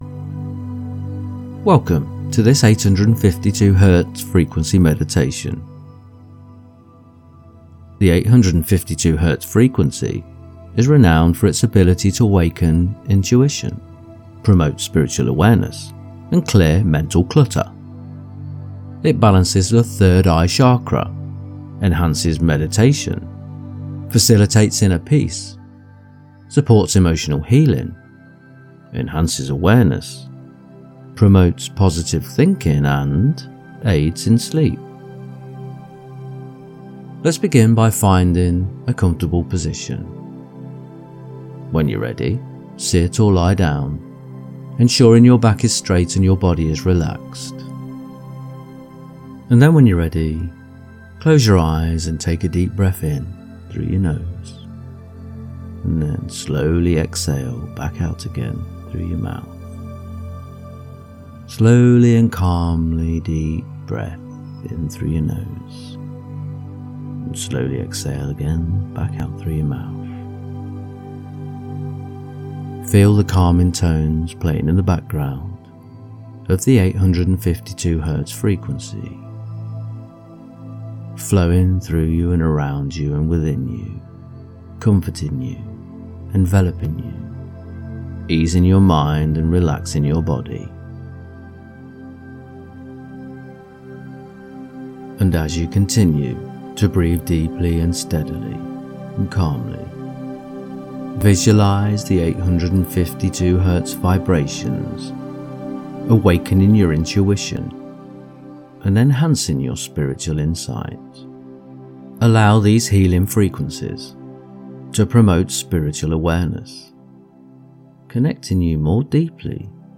Let the clarifying tones of 852 Hz sharpen your mind, helping you stay focused and productive.
852-meditation.mp3